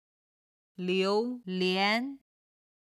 軽声の音は音源の都合上、四声にて編集しています。
榴莲　(liú lián)　ドリアン
22-liu2lian2.mp3